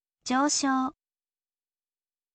joushou